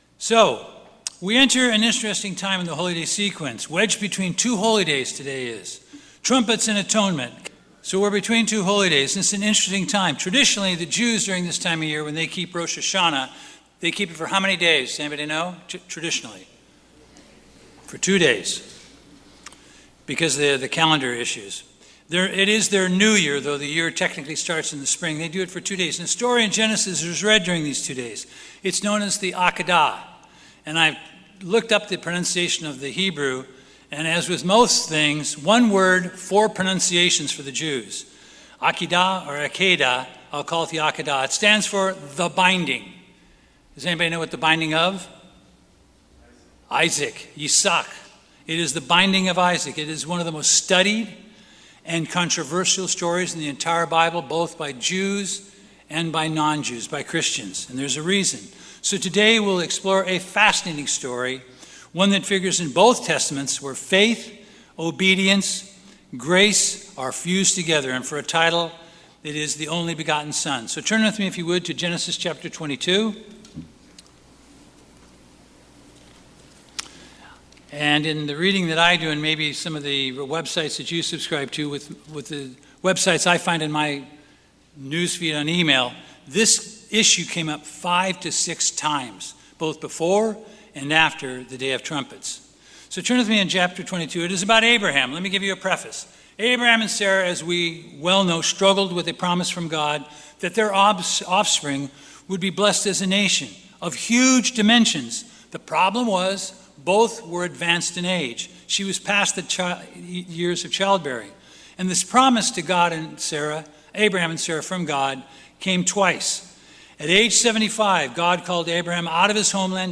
Sermons
Given in Los Angeles, CA Bakersfield, CA